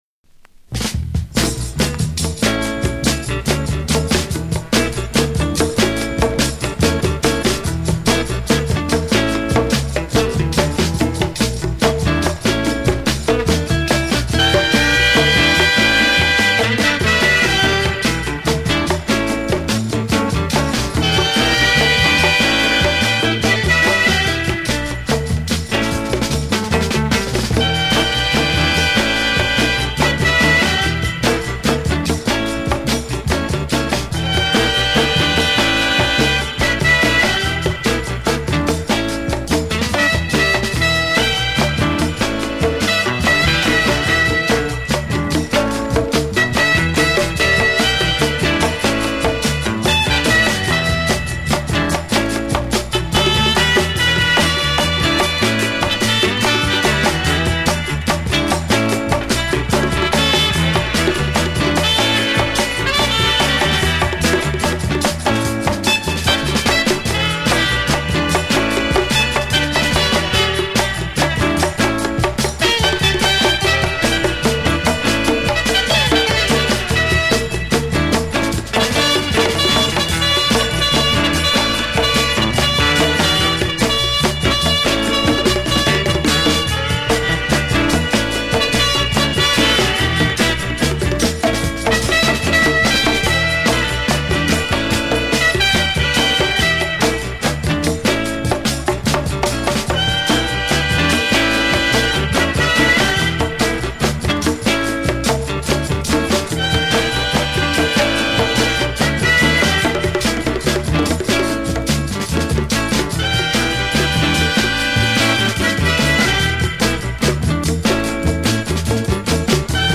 LATIN-FUNK (-JAZZ)